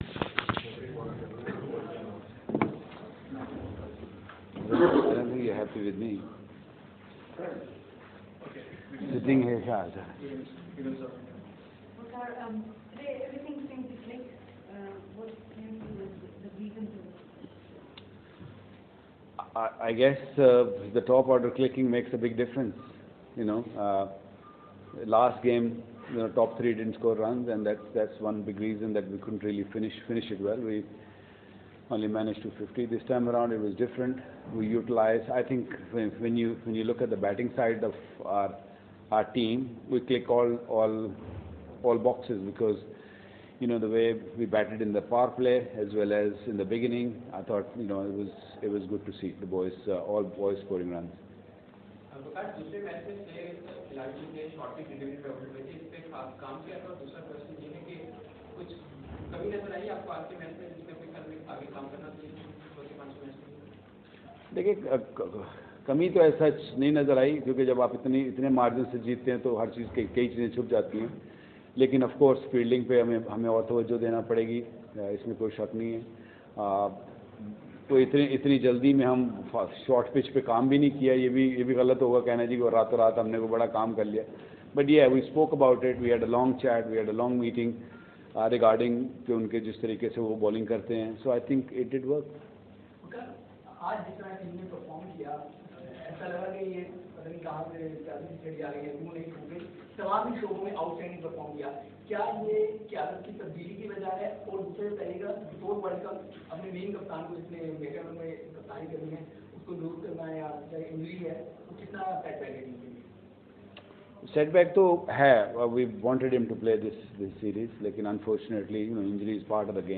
Waqar Younis Press Conference post match 3rd ODI at Sharjah (Audio)